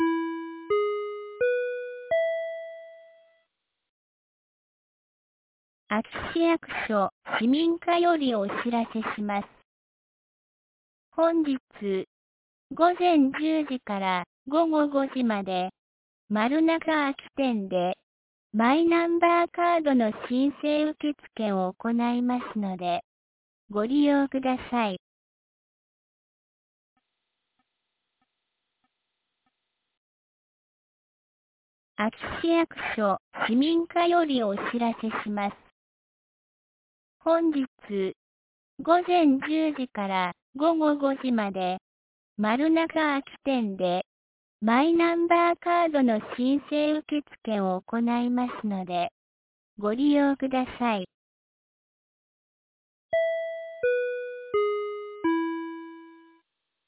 2022年12月03日 09時45分に、安芸市より全地区へ放送がありました。